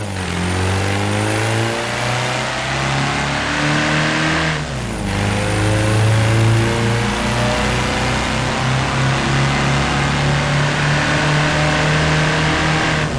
Home gmod sound vehicles tdmcars beetle